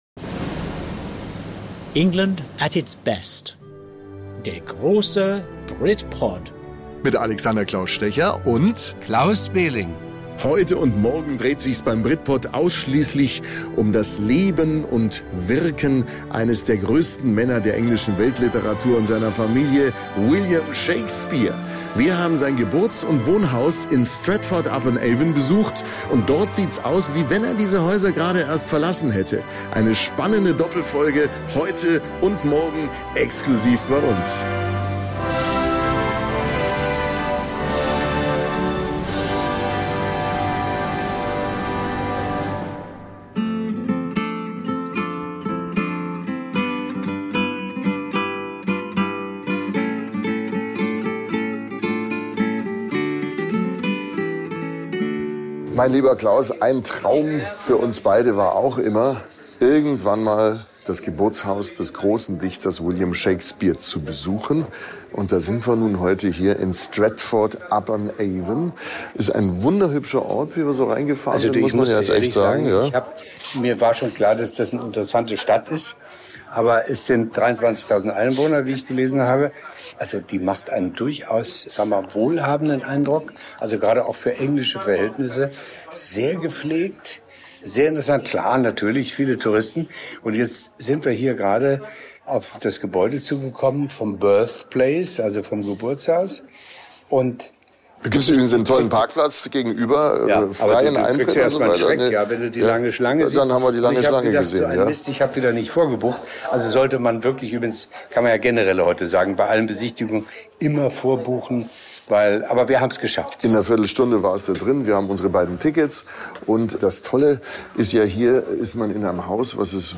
Im malerischen Stratford-upon-Avon erkunden sie den Ort, an dem der berühmte Dramatiker mit seiner Familie aufwuchs.